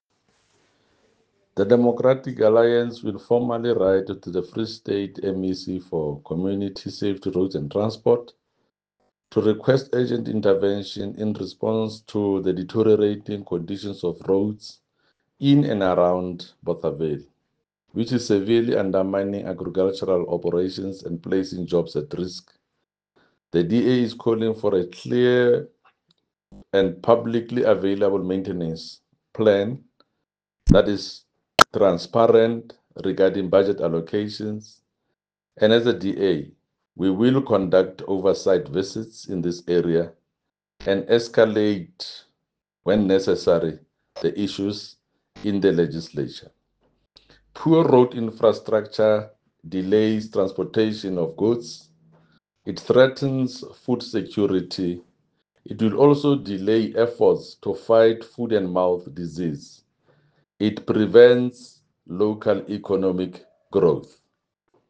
Sesotho soundbites by Jafta Mokoena MPL and